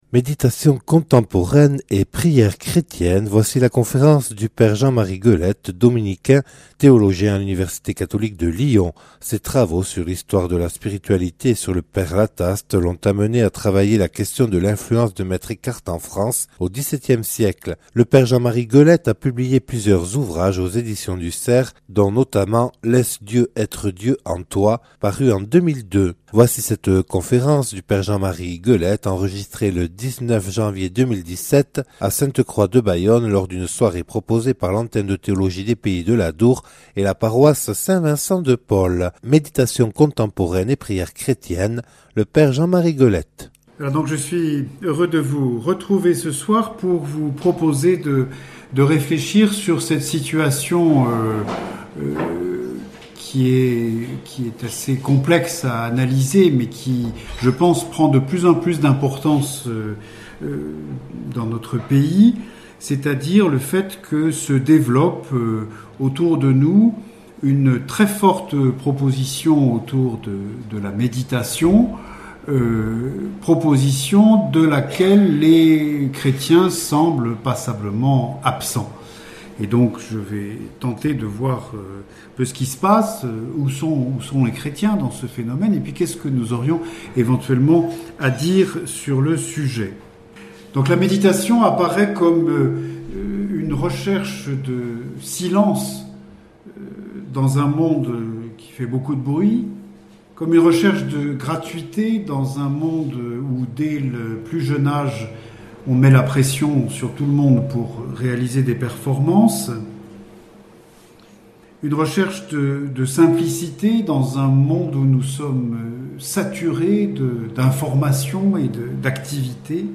Enregistré le 19/01/2017 à Sainte Croix de Bayonne lors d'une soirée proposée par l'Antenne de Théologie des Pays de l'Adour